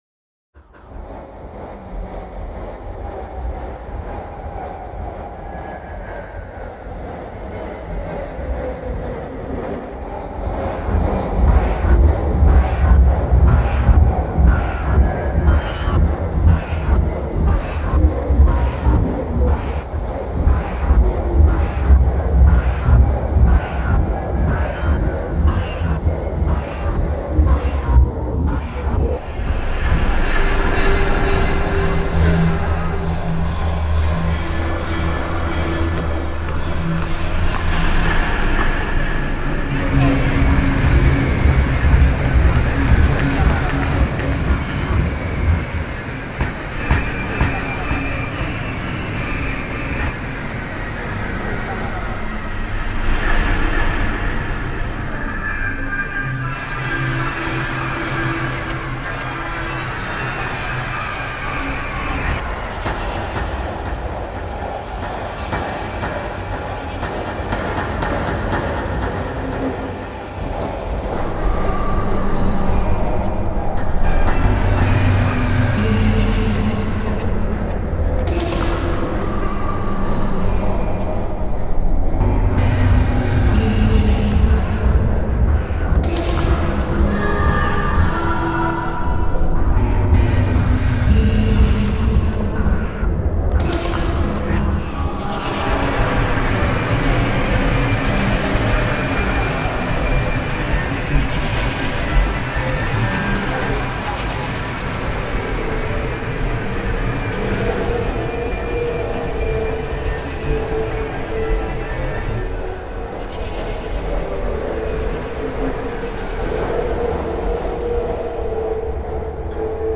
Shanghai spring festival reimagined